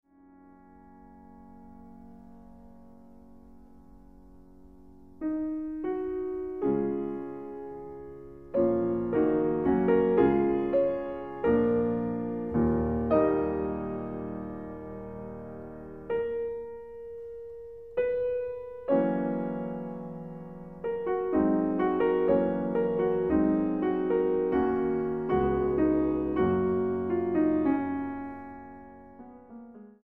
pianista.